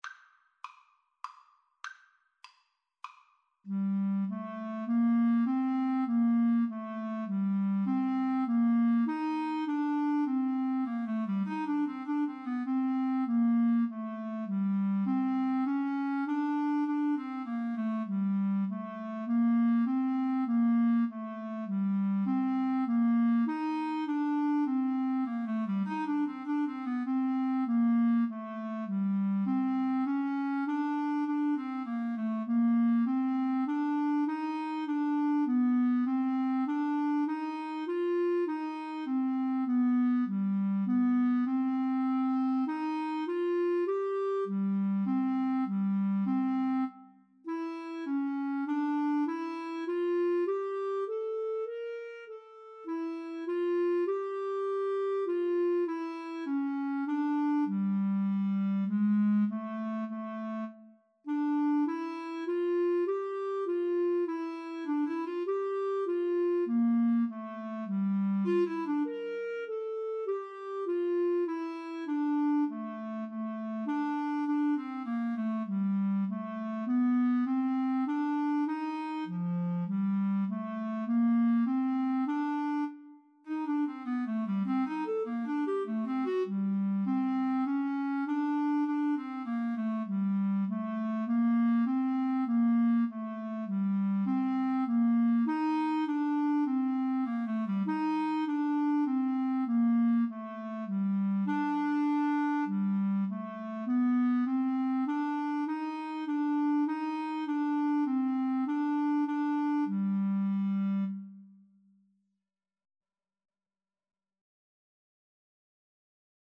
3/4 (View more 3/4 Music)
Tempo di menuetto